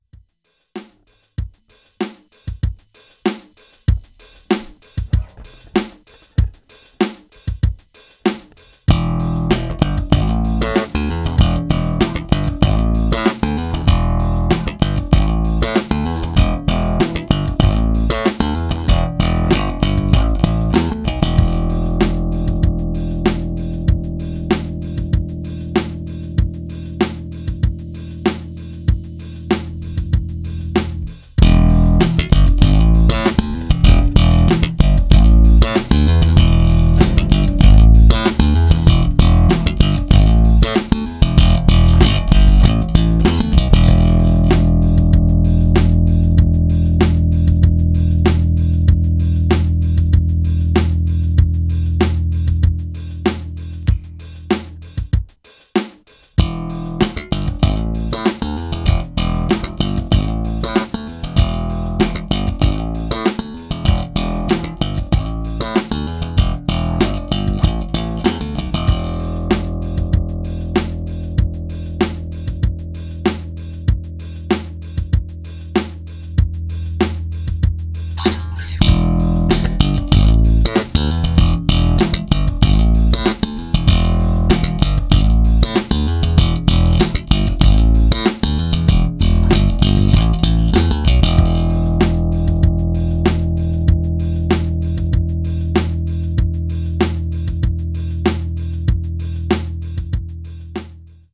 Do 0:53.... v režimu humbucker
od 0:54... 2x single (slabší signál)